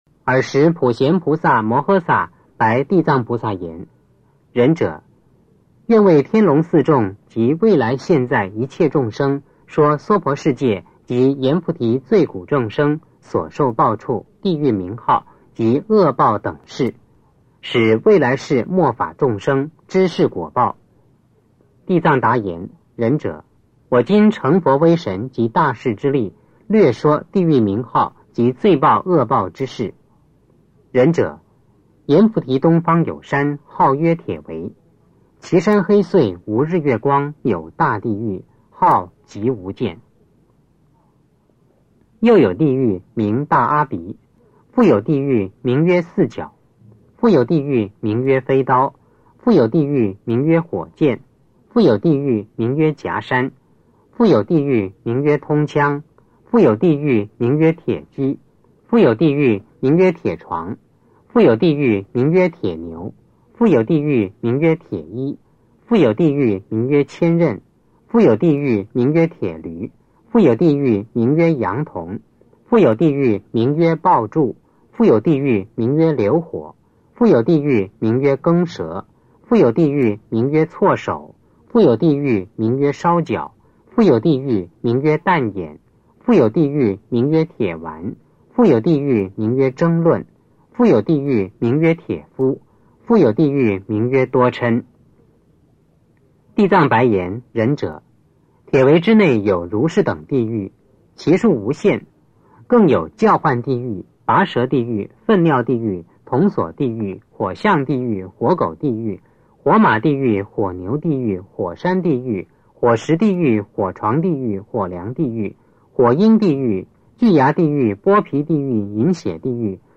地藏经(男声念诵）5
地藏经(男声念诵）5 诵经 地藏经(男声念诵）5--未知 点我： 标签: 佛音 诵经 佛教音乐 返回列表 上一篇： 地藏经(男声念诵）4 下一篇： 地藏经(男声念诵）8 相关文章 地藏经(唱诵)--承天禅寺僧众唱诵 地藏经(唱诵)--承天禅寺僧众唱诵...